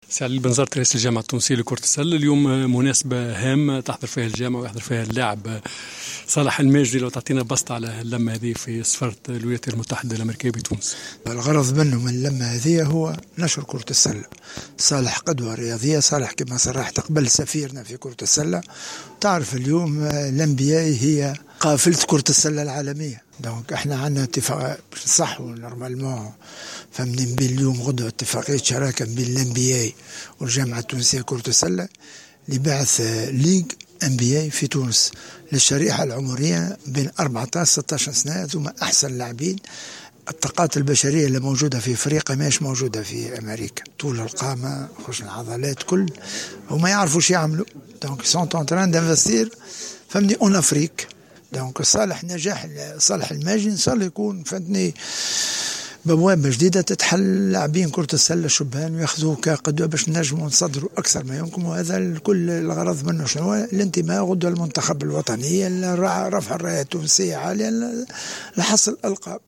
عقد اليوم الدولي التونسي و لاعب دالاس مافريس الأمريكي صالح الماجري ندوة صحفية بسفارة الولايات المتحدة الأمريكية بتونس للحديث حول تنظيم دورات تكوينية للشبان في رياضة كرة السلة بالتعاون مع رابطة كرة السلة الأمريكية (NBA) و تحت إشراف الجامعة التونسية لكرة القدم في إطار إتفاقية شراكة بين الطرفين.